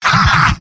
Giant Robot lines from MvM. This is an audio clip from the game Team Fortress 2 .
Demoman_mvm_m_laughshort03.mp3